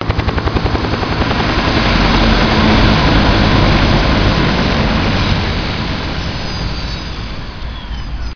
heli_shutdown.ogg